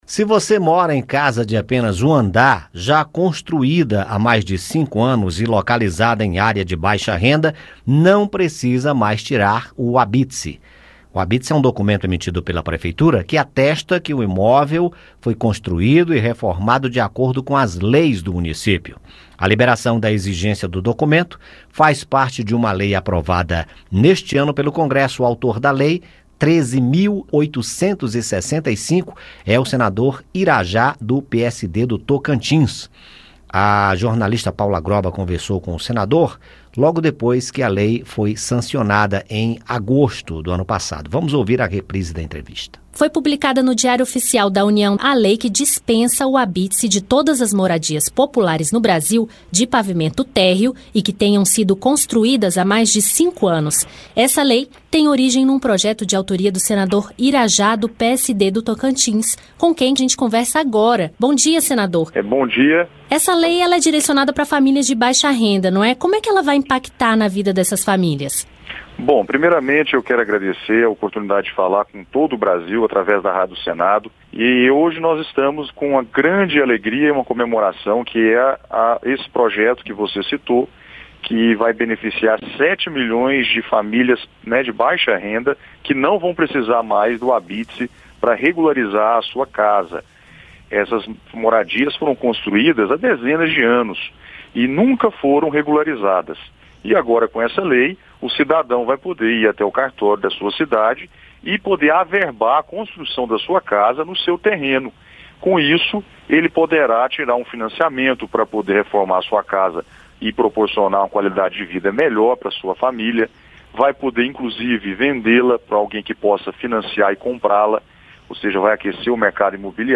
E, nesta segunda-feira (6), reprisamos entrevista com o autor da lei, senador Irajá (PSD-TO). Ouça o áudio com a entrevista.